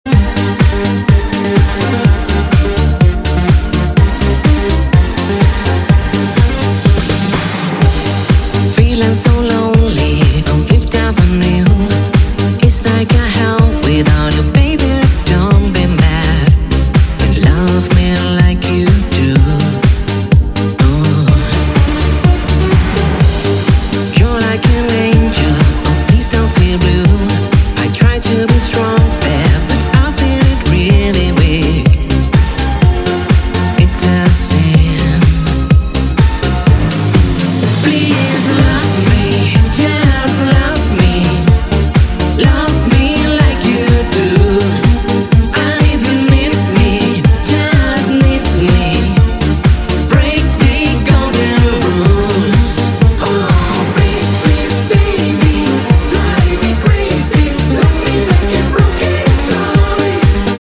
• Качество: 192, Stereo
поп
женский голос
энергичные
ретро
цикличные